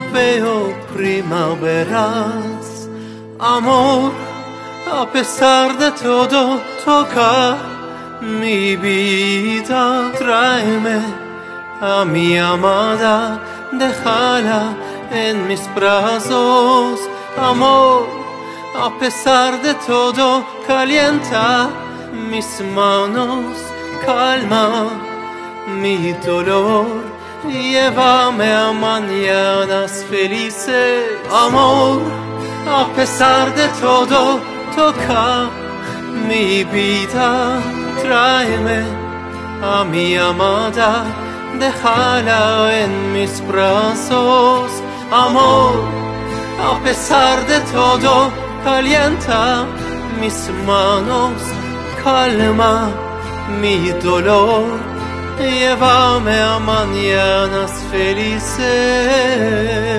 Miami’de Turkish Drama Gala’da yemekte